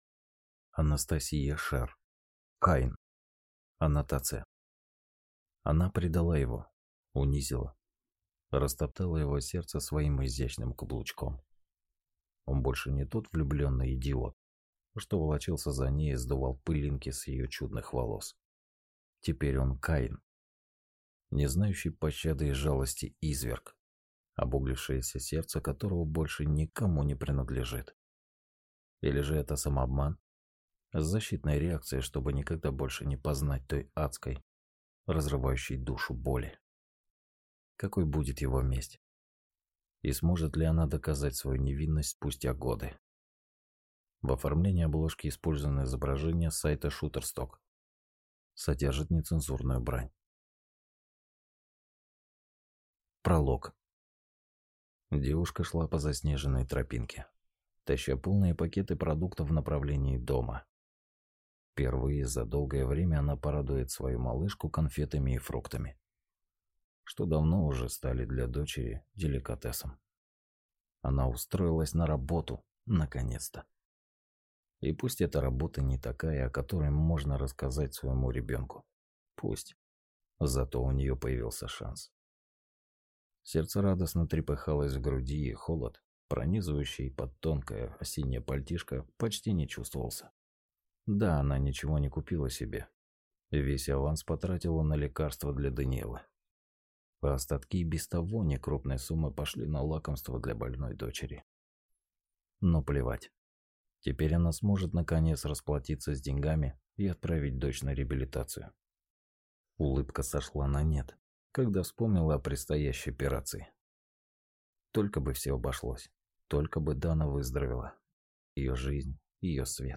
Аудиокнига Каин | Библиотека аудиокниг
Прослушать и бесплатно скачать фрагмент аудиокниги